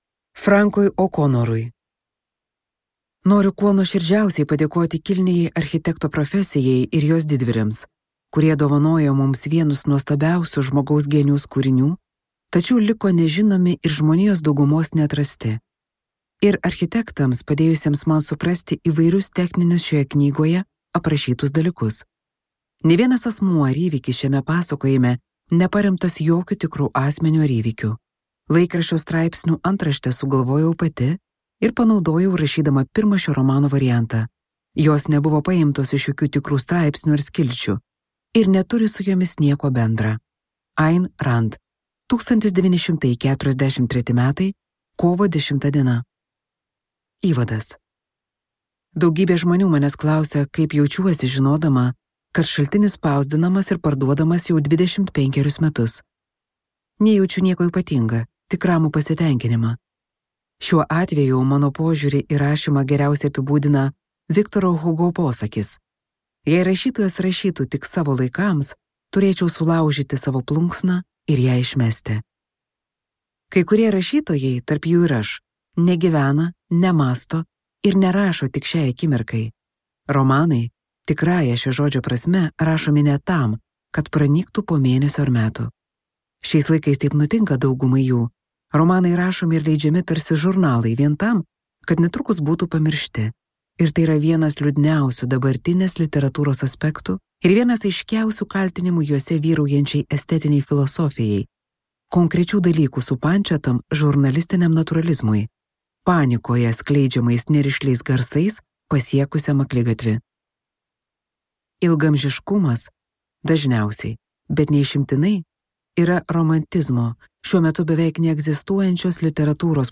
Šaltinis | Audioknygos | baltos lankos